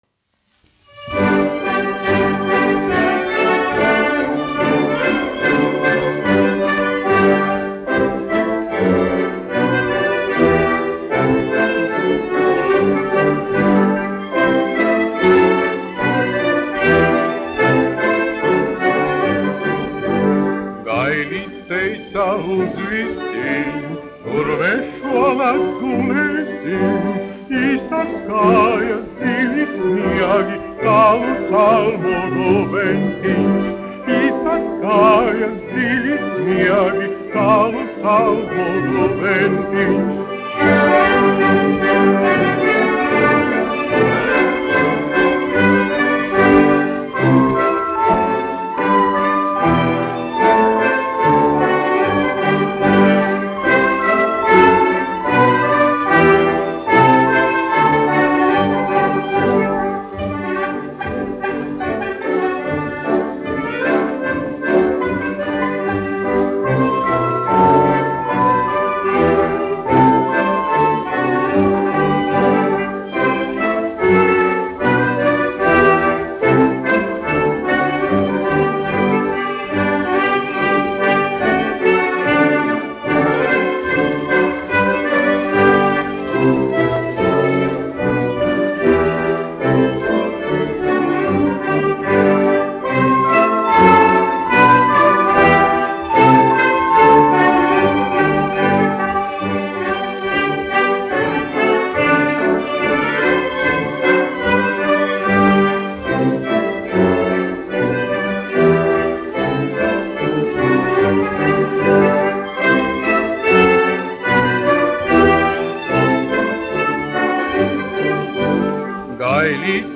1 skpl. : analogs, 78 apgr/min, mono ; 25 cm
Latviešu tautas dejas
Skaņuplate
Latvijas vēsturiskie šellaka skaņuplašu ieraksti (Kolekcija)